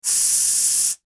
Air Hiss
Air_hiss.mp3